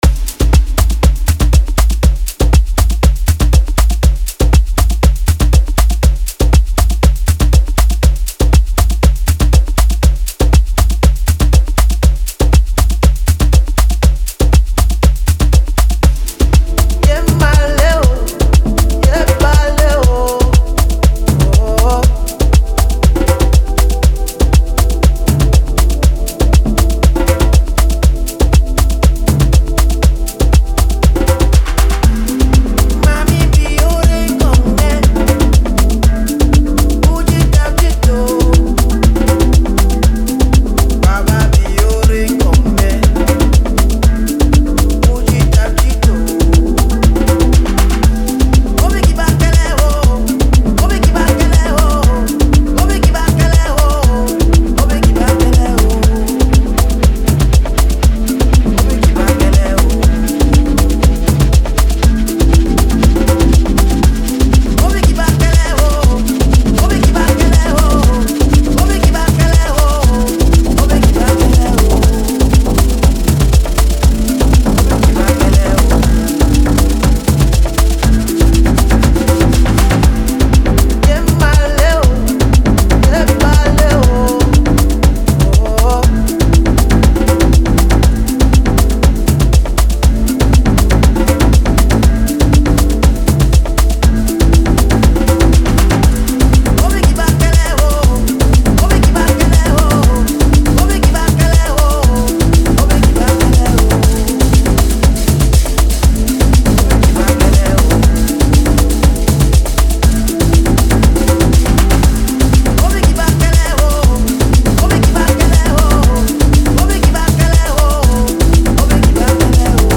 Chill Out / Lounge Deep House House